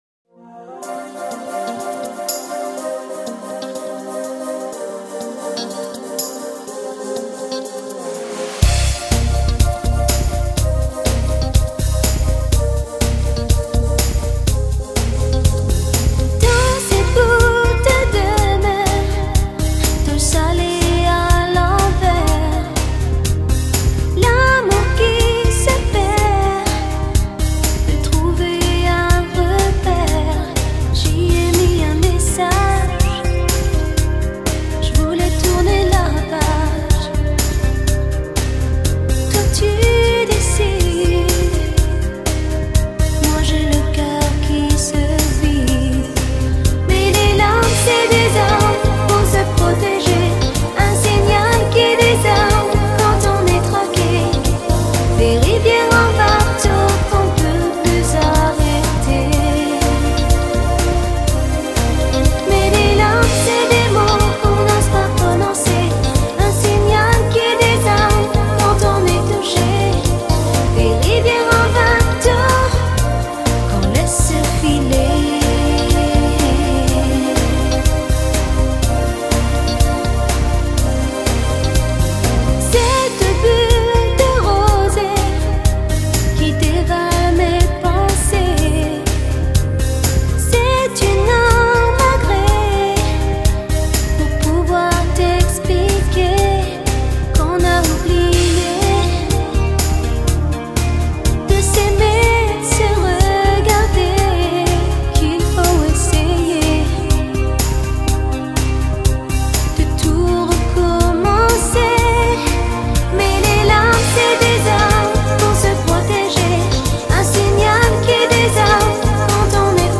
有人叫她法国温婉天后，有人叫她疗伤天后，她的歌声，只要飘扬在空气中便能舒缓人的心灵。